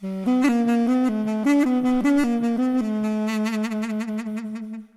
Nefesli Çalgilar Samples
Asagida Dinlediginiz Sample Sesleri direk Orgla Calinip MP3 Olarak Kayit edilmistir
mey.wav